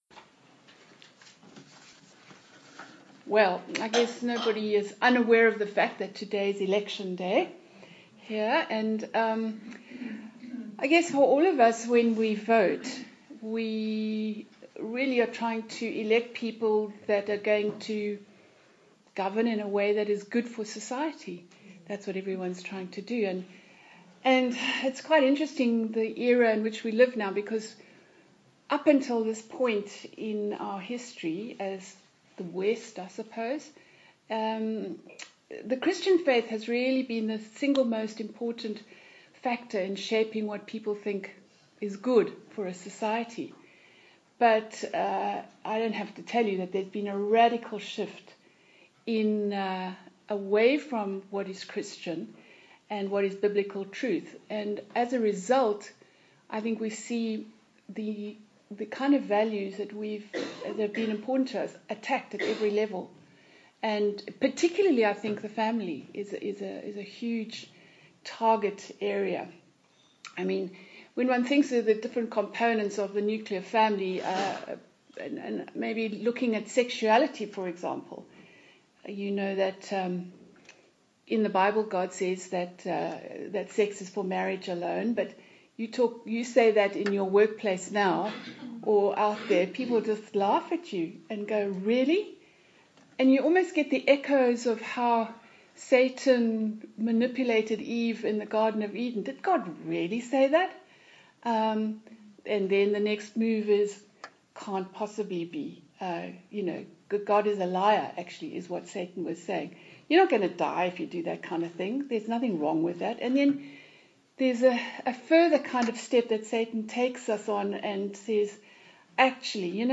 This talk took place at the Women Of Westminster event in May 2019.